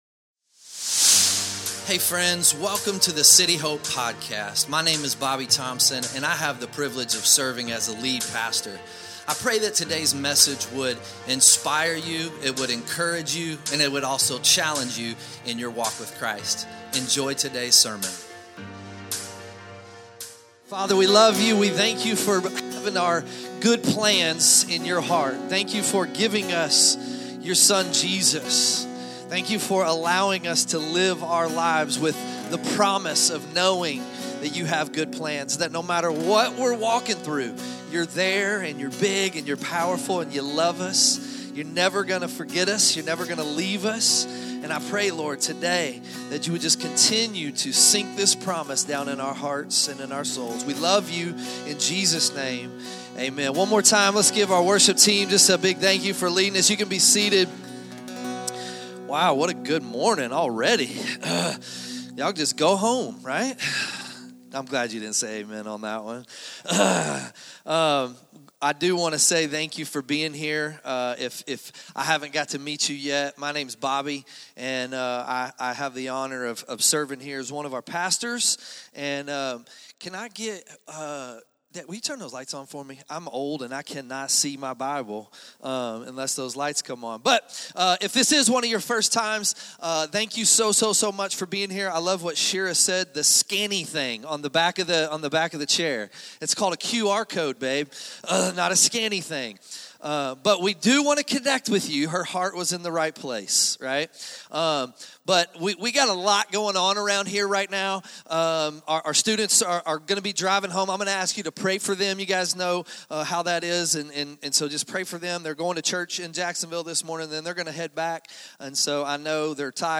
2025 Sunday Morning The false teachers in Colossae said